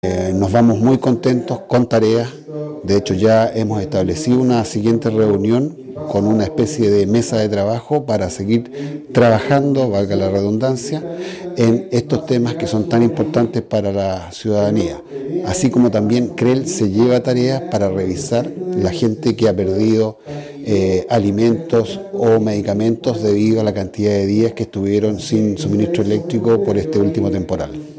Director Regional de la Superintendencia de Electricidad y Combustibles, Álvaro Loma-Osorio, se refirió a los alcances de la instancia.